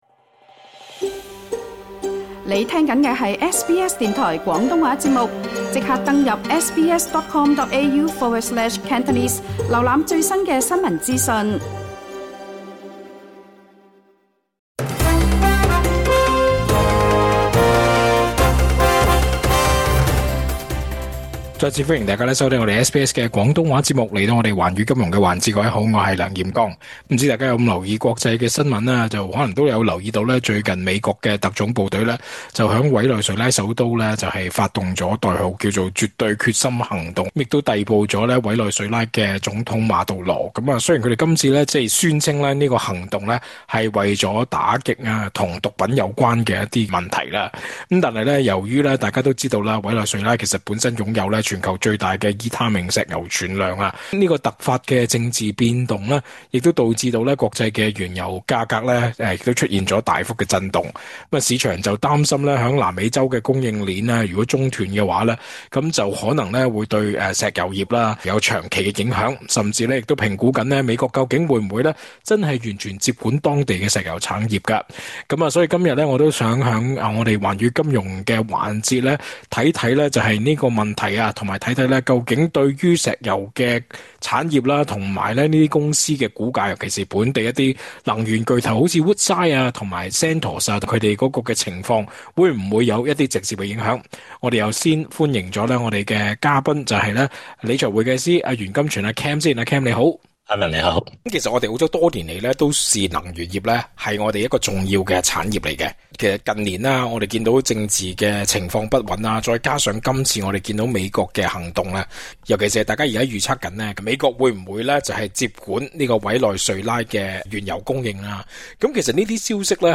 詳盡訪問